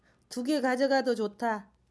Dialect: Jeju-si
[ du-ge ]